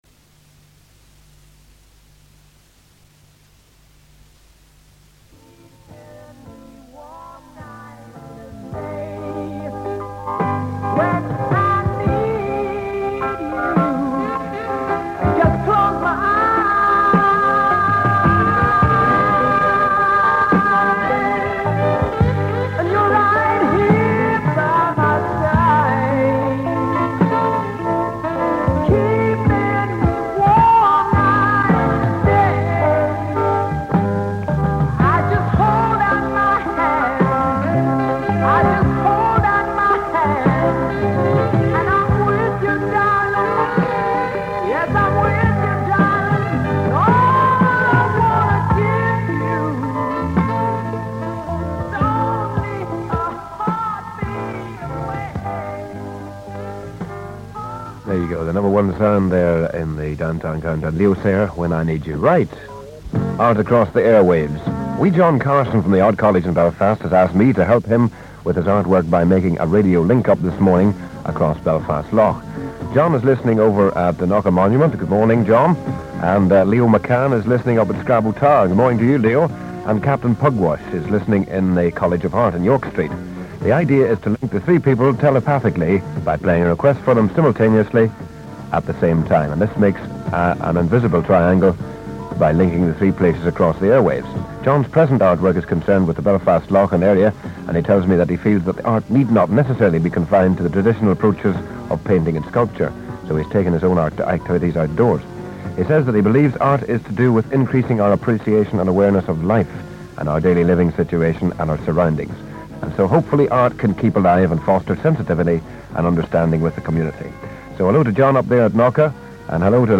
Hilltop Triangle: A conceptual triangle was created between 3 prominent geographical locations when a DJ on Radio Downtown made a prearranged announcement and played a breakfast time request for 3 people listening in from each location with transistor radios. To listen to a sample of the radio broadcast: